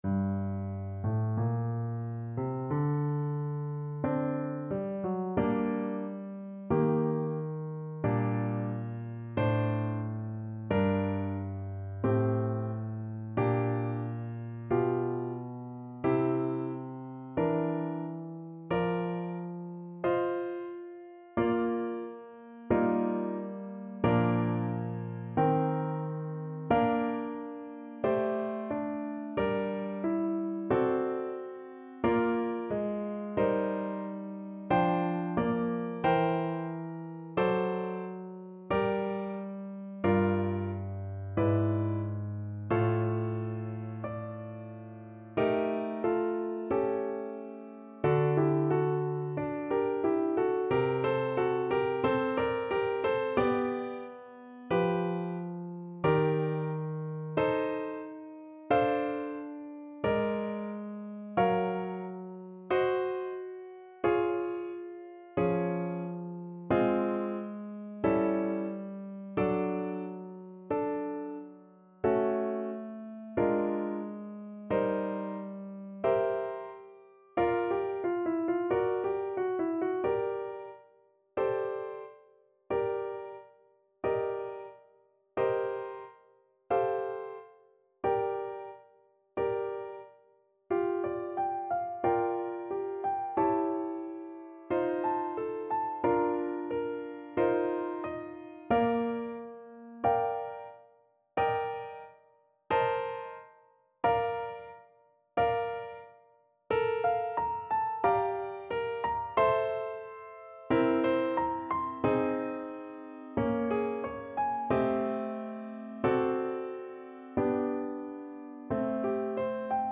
Play (or use space bar on your keyboard) Pause Music Playalong - Piano Accompaniment Playalong Band Accompaniment not yet available reset tempo print settings full screen
G minor (Sounding Pitch) D minor (French Horn in F) (View more G minor Music for French Horn )
Adagio, molto tranquillo (=60) =45
Classical (View more Classical French Horn Music)